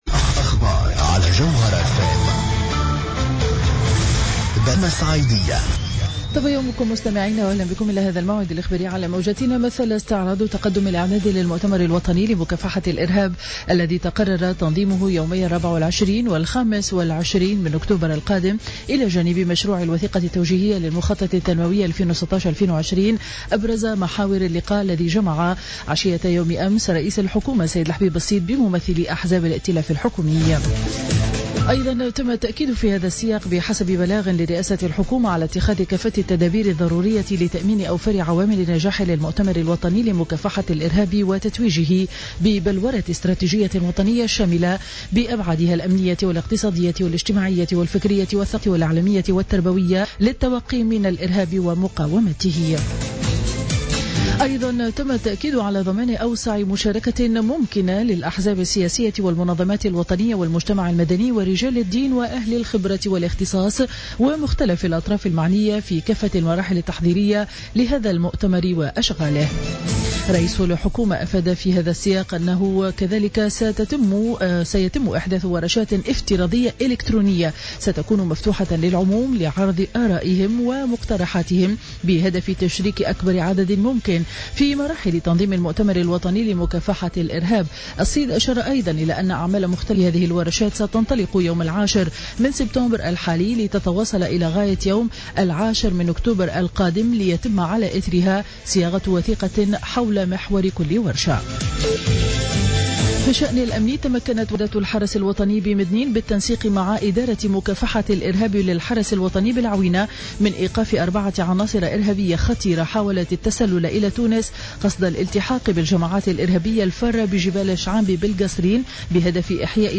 نشرة أخبار السابعة صباحا ليوم الجمعة 4 سبتمبر 2015